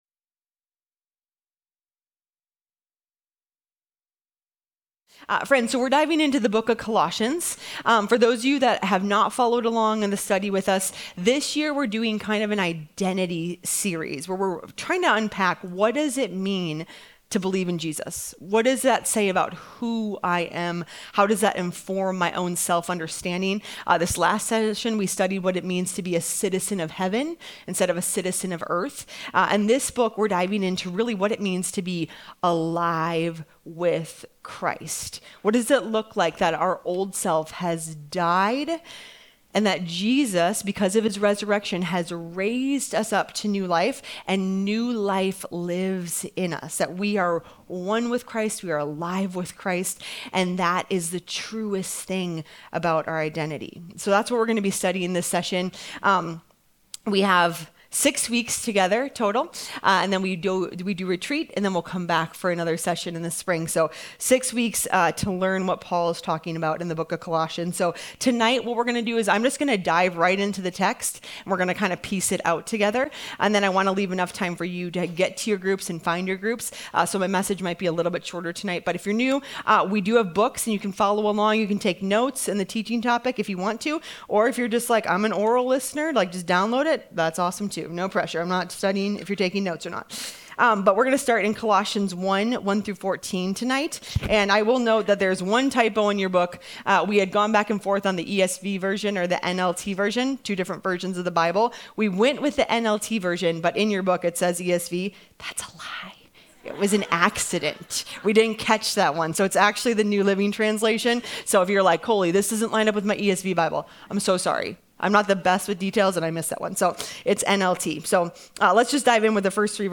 Audio Messages From The Women's Ministry at Christian Assembly Church in Eagle Rock, California